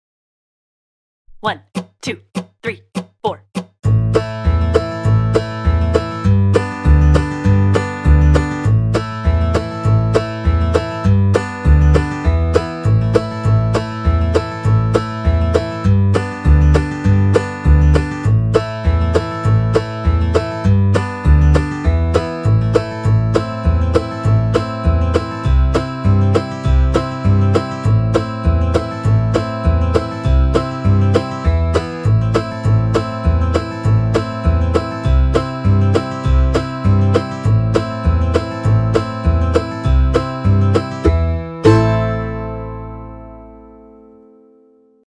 VIRTUAL BLUEGRASS JAM
Key: A Major / A Minor
Tempo: 100 BPM
Backing Track
Cattle-In-The-Cane-100-bpm.mp3